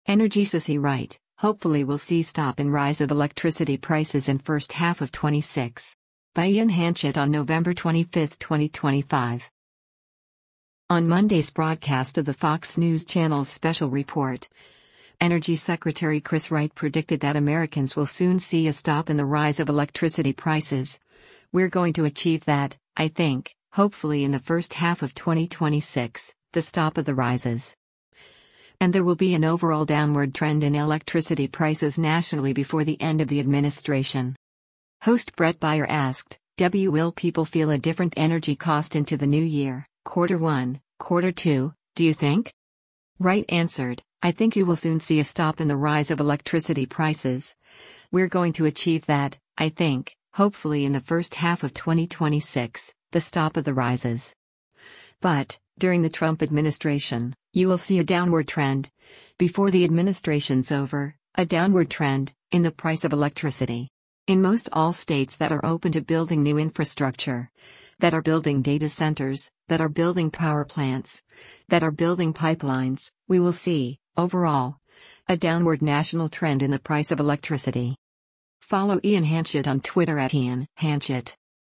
On Monday’s broadcast of the Fox News Channel’s “Special Report,” Energy Secretary Chris Wright predicted that Americans “will soon see a stop in the rise of electricity prices, we’re going to achieve that, I think, hopefully in the first half of 2026, the stop of the rises.”
Host Bret Baier asked, “[W]ill people feel a different energy cost into the new year, quarter 1, quarter 2, do you think?”